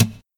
hit_3.ogg